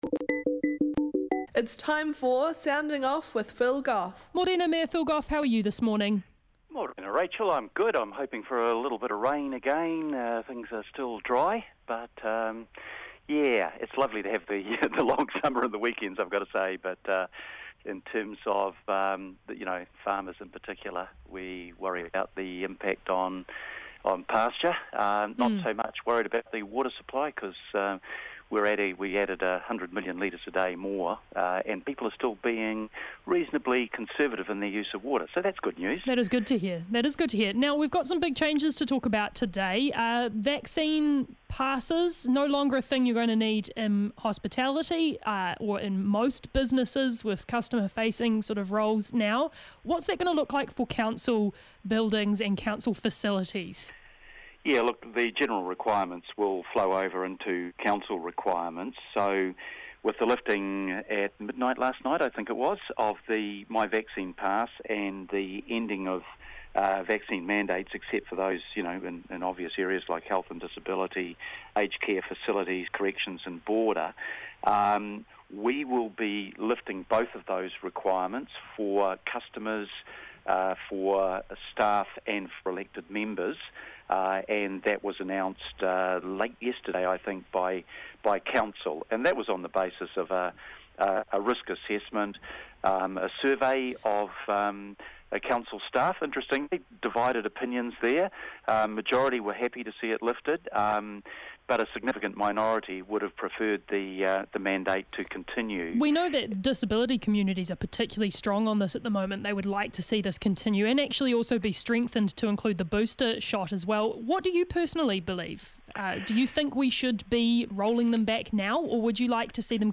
The Mayor phones up to talk about vaccine mandates ending tonight, Auckland Transport's new parking strategy, and helipad consents for the Hauraki Gulf.